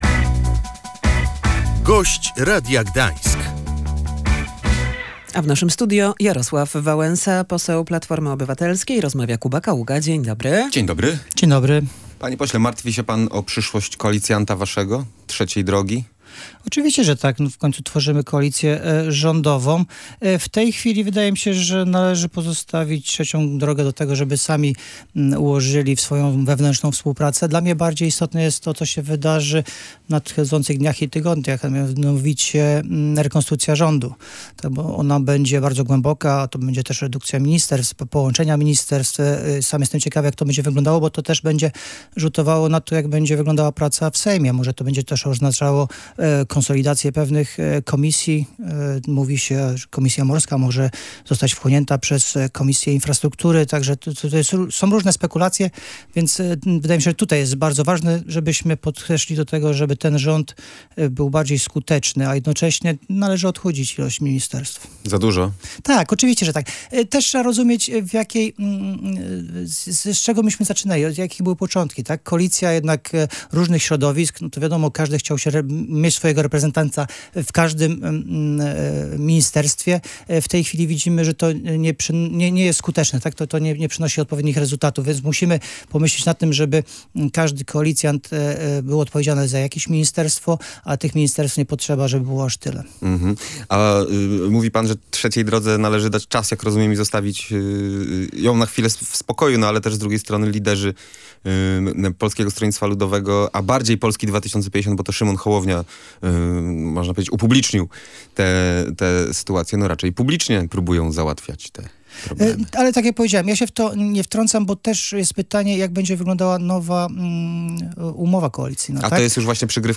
To będzie bardzo głęboka rekonstrukcja rządu. Niektóre ministerstwa zostaną zlikwidowane, inne połączone – poinformował na naszej antenie Jarosław Wałęsa, poseł Platformy Obywatelskiej. Dodał, że zmiany bezpośrednio przełożą się na pracę w sejmie.